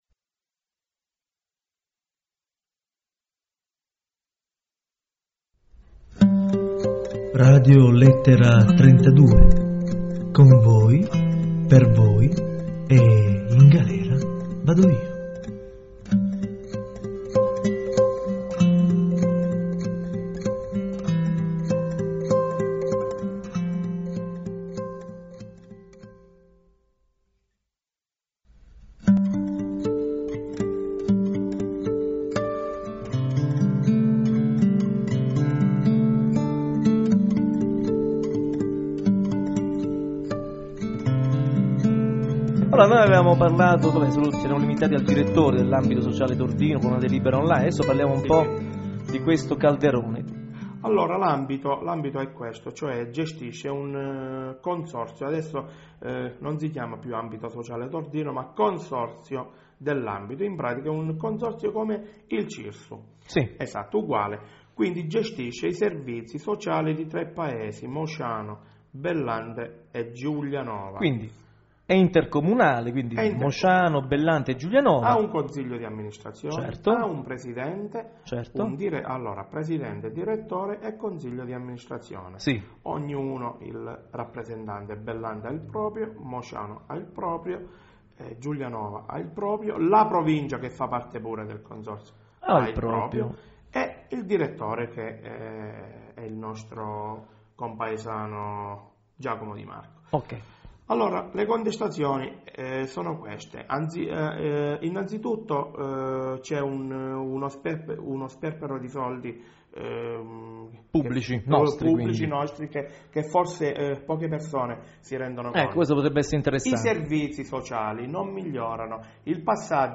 L'intervista è divisa in tre parti di circa 7 minuti l'una.